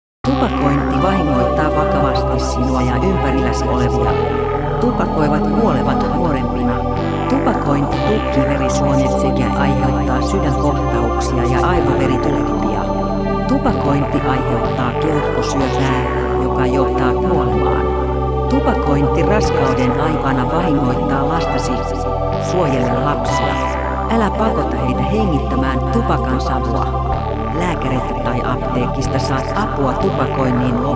EXPERIMENTAL MUSIC ; AMBIENT MUSIC